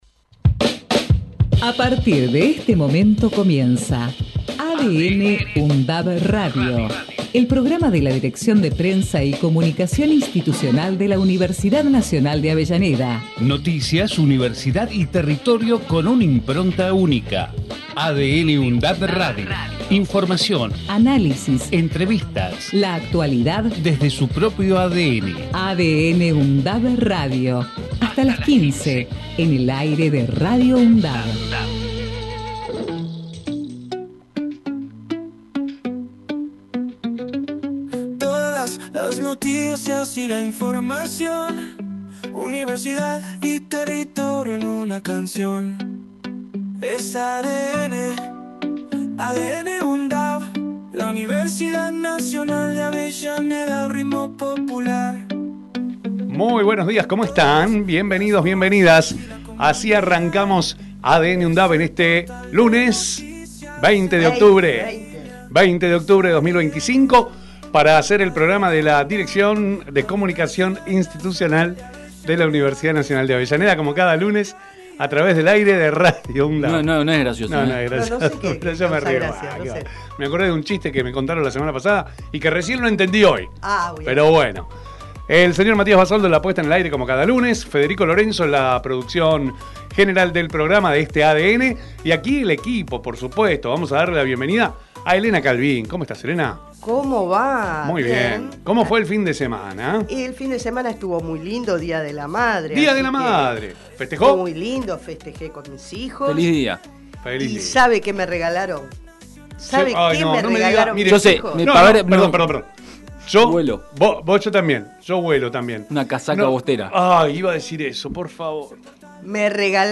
ADN | UNDAV – Radio. Texto de la nota: El programa de la Dirección de Prensa y Comunicación Institucional de la Universidad Nacional de Avellaneda en su emisora Radio UNDAV, busca transmitir la impronta de la Universidad, su identidad, su ADN de una forma actual y descontracturada, con rigurosidad y calidad informativa. Noticias, universidad y territorio son los tres ejes que amalgaman la nueva propuesta a través de la imbricación y la interrelación de las temáticas que ocupan y preocupan a la comunidad local, zonal y nacional desde una mirada universitaria, crítica y constructiva a través de voces destacadas del mundo académico, político, cultural y social.
ADN|UNDAV – Radio tiene la vocación de ser un aporte en tal sentido, a través de secciones como “Temas de la Uni”, “Entrevistas”, “Lo que pasa” y “En comunidad”.